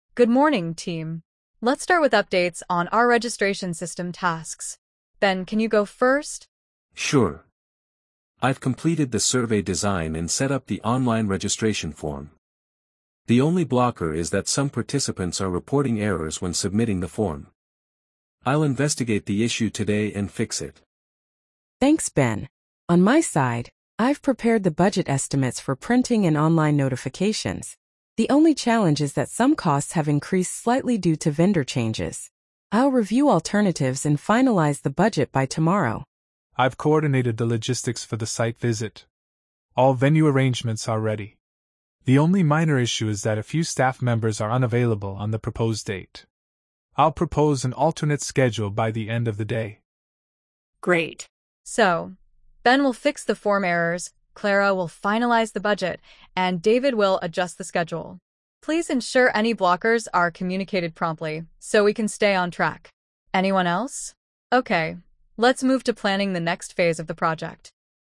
🤝 The team gives updates, reports issues, and plans solutions.